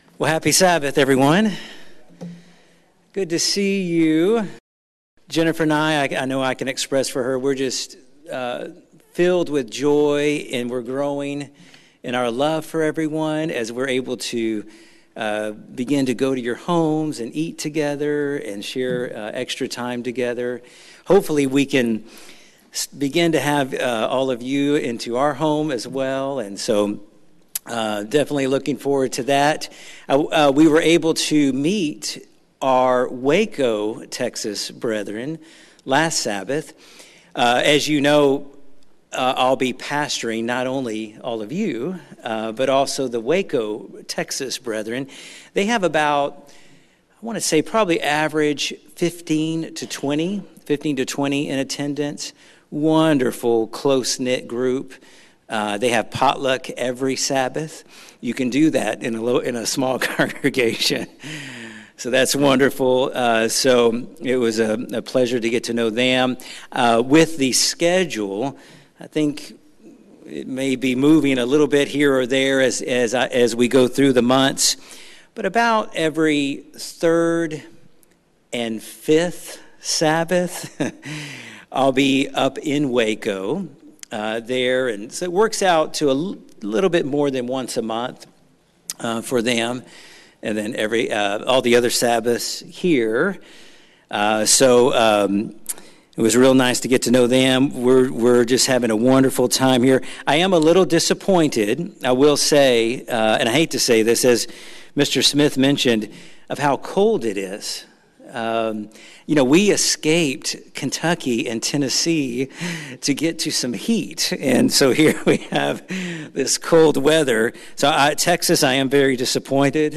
This sermon focuses on the book of Ecclesiastes where Solomon searches for the meaning of life. He considers some of the main ways humans search for the meaning of life: Knowledge, pleasure, materialism, etc. But in his searches Solomon left God out of the process, therefore it is not until we reach the end of the book where he considers God, that we find the true meaning to life.